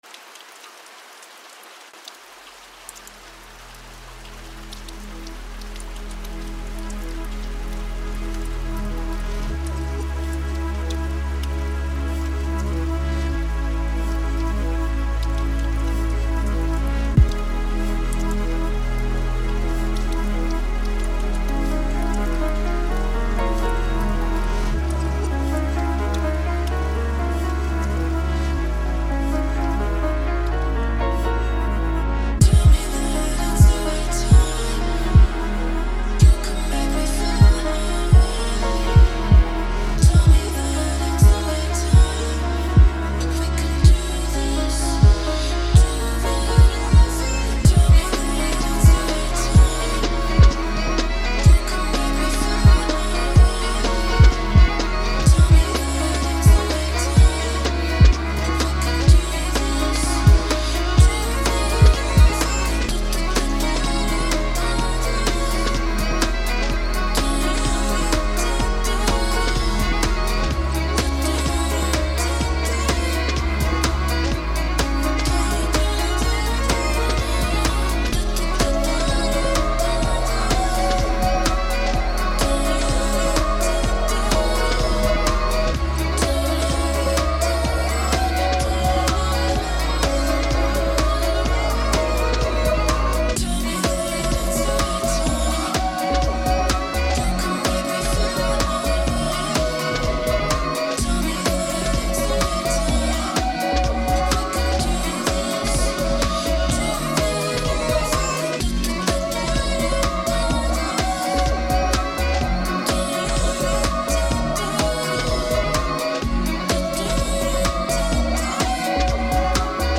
It brings violins into the mix and chills things out.
Tempo 126BPM (Allegro)
Genre Chill House
Type Vocal Music
Mood Chilled